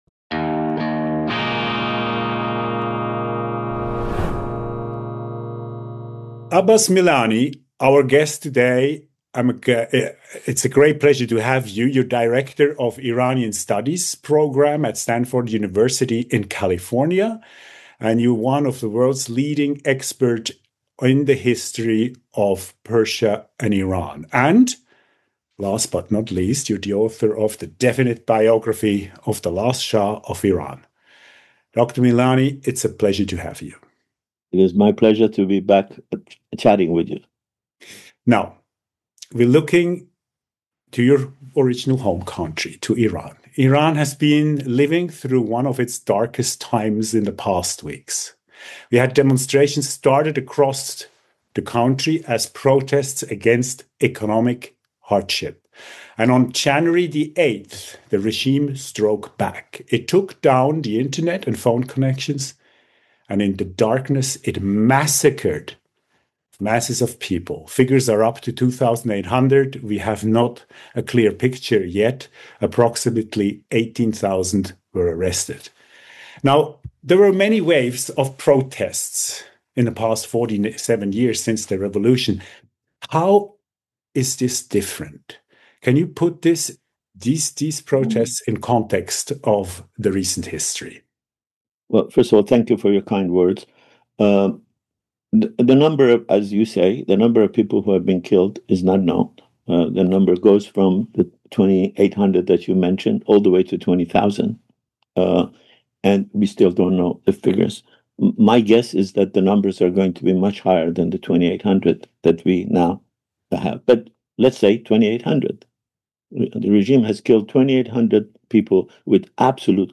Iran: Hat das Regime nach Massenerschiessungen den Volksaufstand gebrochen? Lässt Trump die Iraner im Stich? Iran-Forscher Abbas Milani im Gespräch